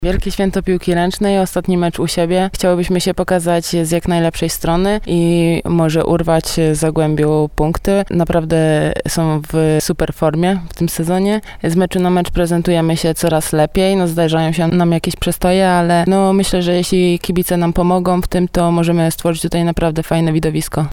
Wypowiedzi przedmeczowe